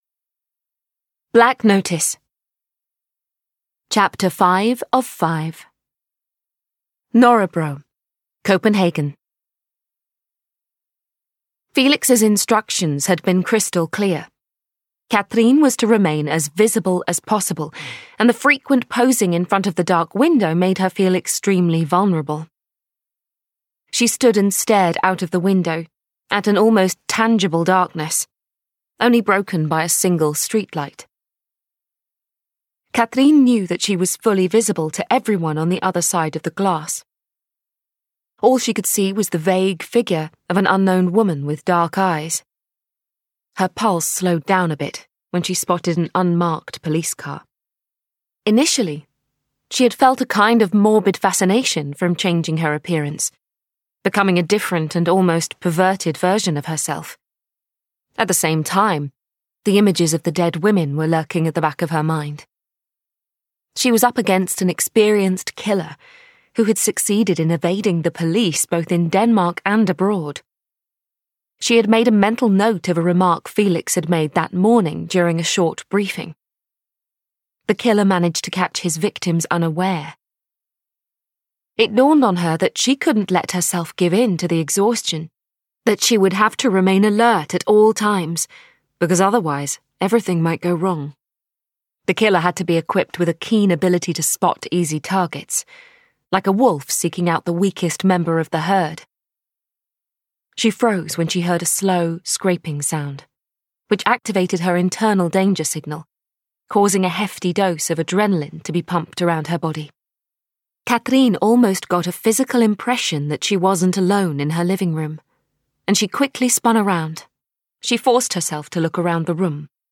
Black Notice: Episode 5 (EN) audiokniha
Ukázka z knihy